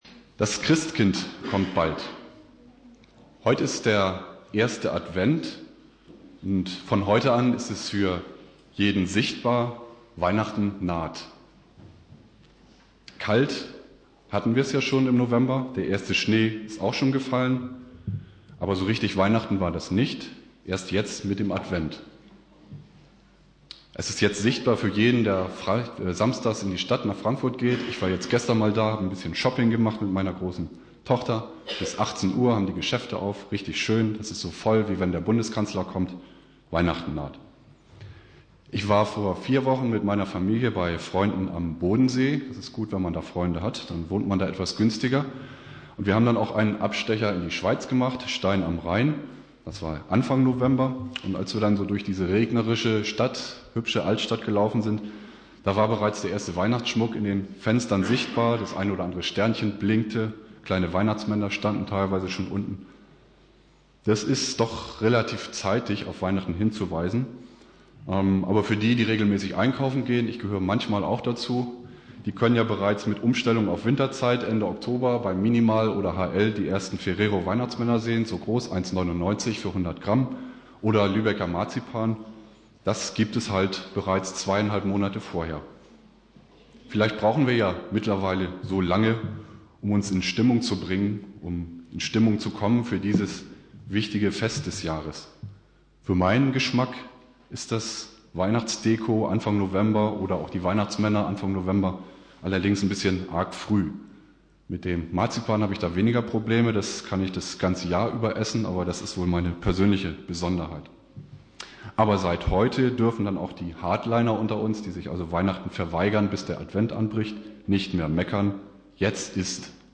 Predigt
1.Advent